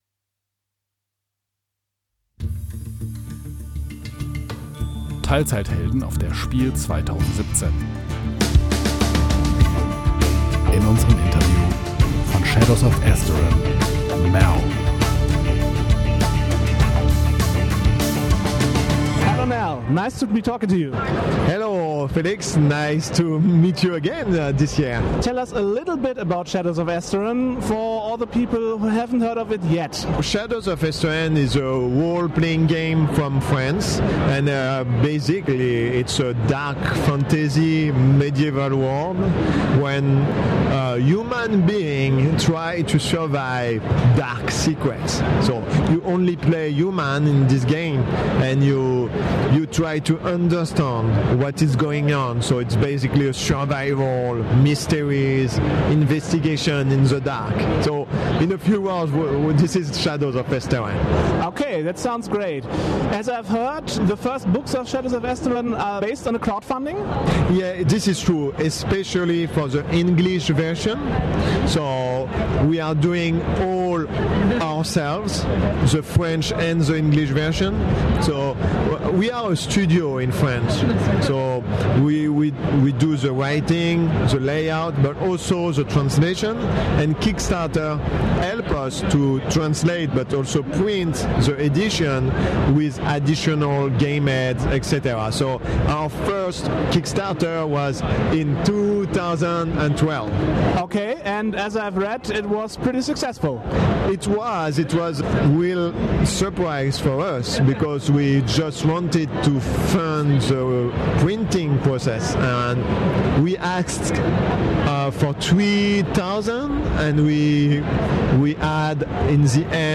interview_shadows_of_esteren_final.mp3